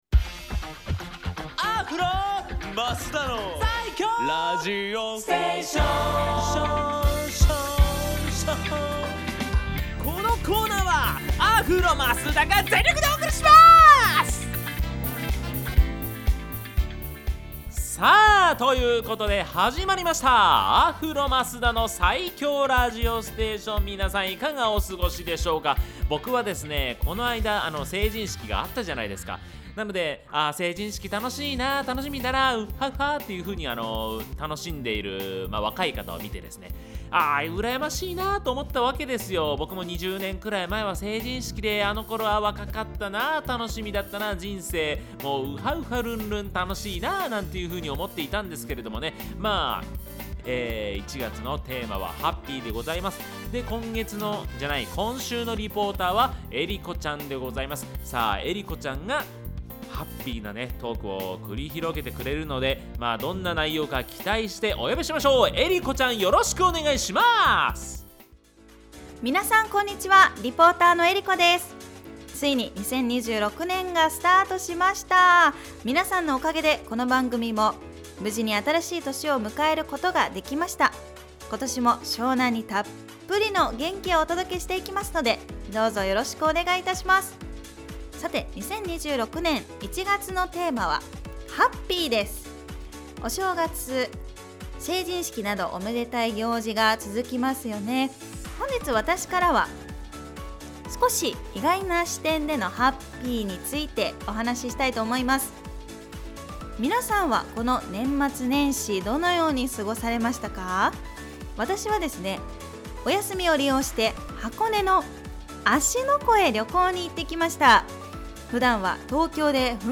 こちらのブログでは、FM83.1Mhzレディオ湘南にて放送されたラジオ番組「湘南MUSICTOWN Z」内の湘南ミュージックシーンを活性化させる新コーナー！
トークの後半にお届けしたのは、湘南が誇るスター！